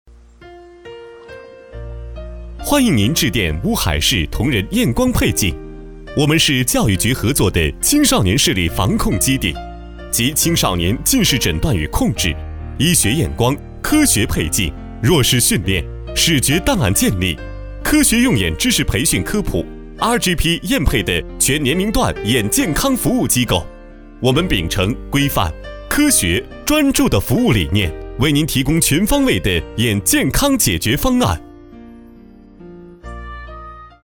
浑厚专题配音
国语配音
男187-彩铃-眼镜.mp3